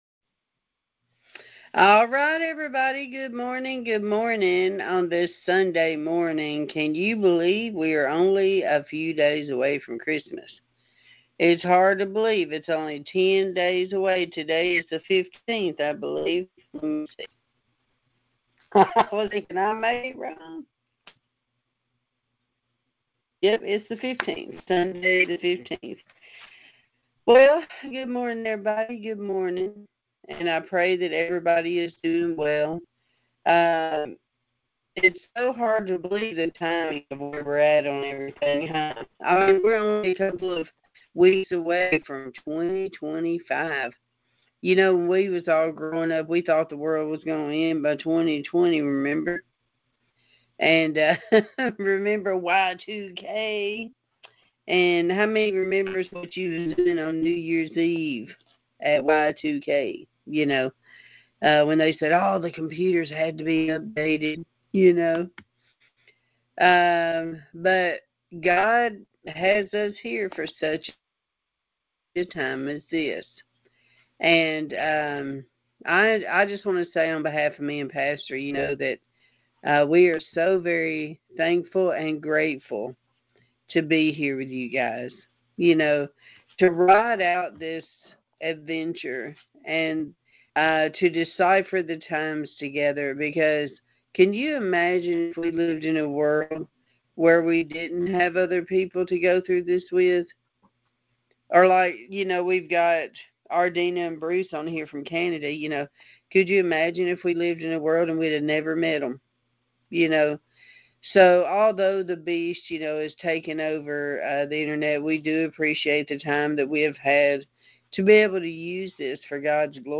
Pastor talked about the many ways we get angry and how God sees that. Very important sermon!